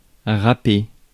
Ääntäminen
IPA : /spiːk/ US : IPA : [spiːk] UK